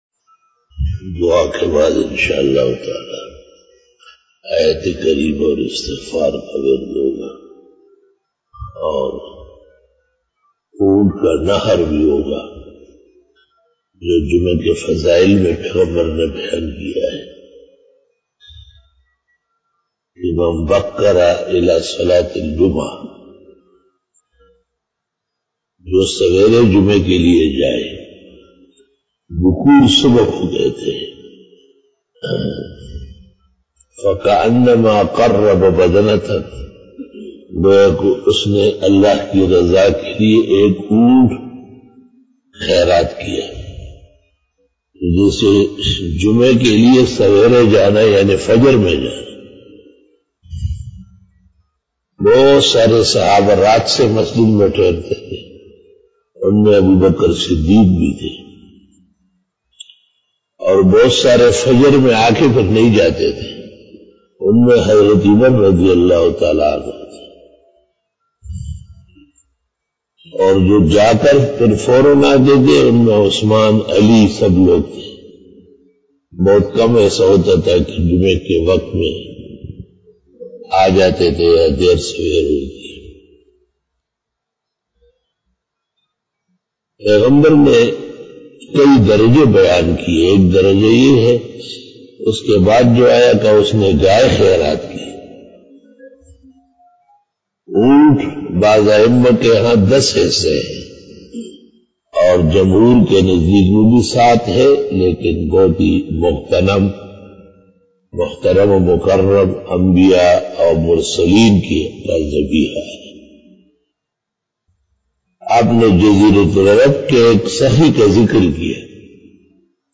After Namaz Bayan
بیان بعد نماز فجر بروز جمعہ